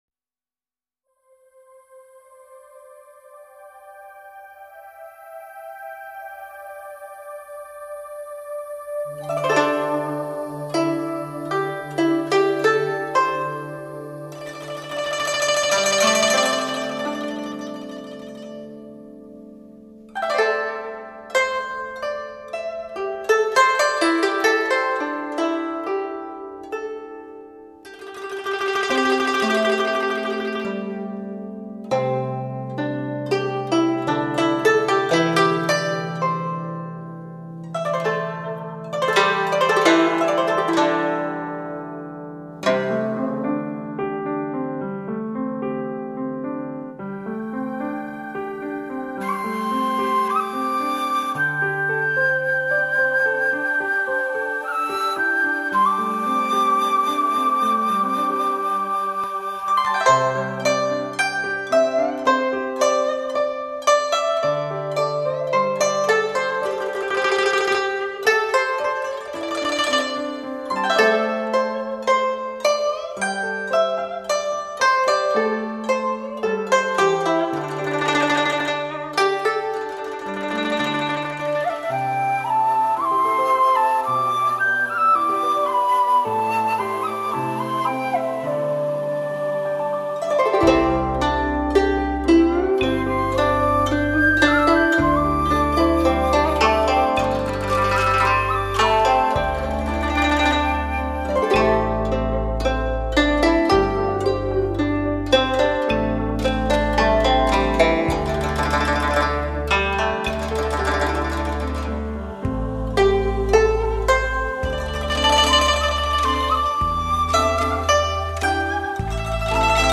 唱片类型：民族音乐
专辑语种：纯音乐
流行、古朴、儒雅反朴归真的音乐韵味也是烦嚣都市人减压、舒缓的发烧珍品。